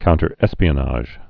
(kountər-ĕspē-ə-näzh, -nĭj)